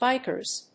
/ˈbaɪkɝz(米国英語), ˈbaɪkɜ:z(英国英語)/